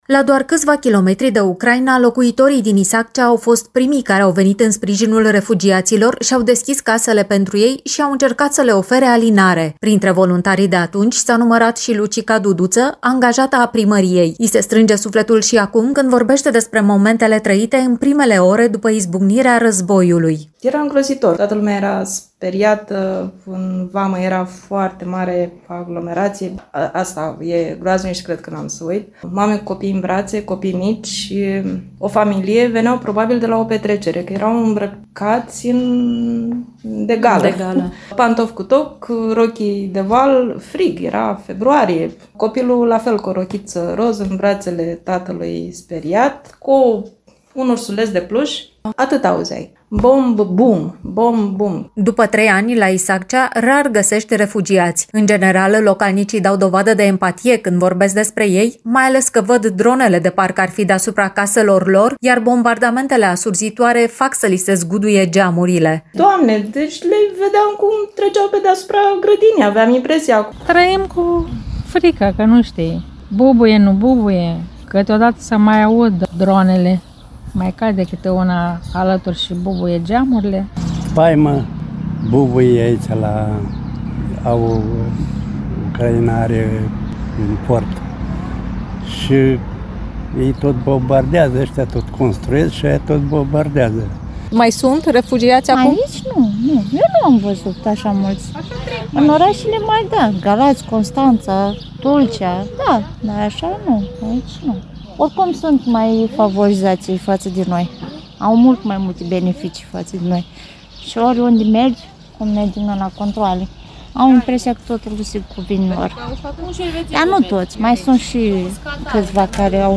reportajul următor